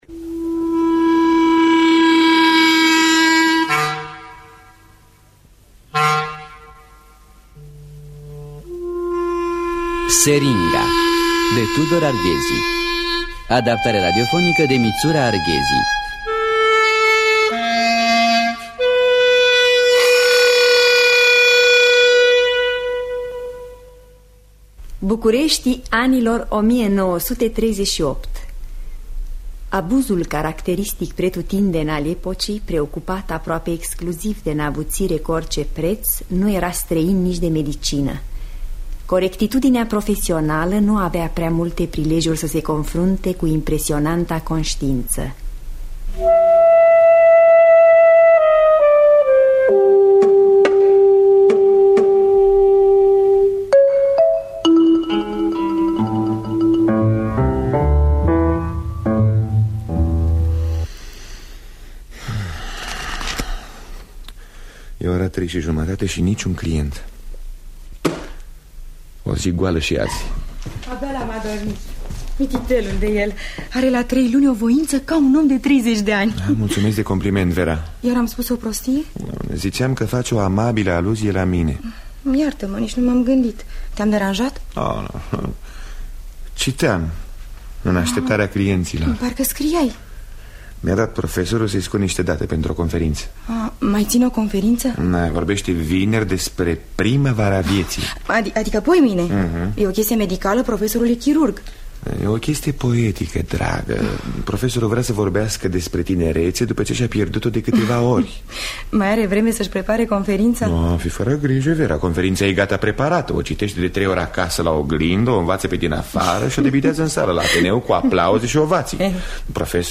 Adaptarea radiofonică de Mitzura Arghezi.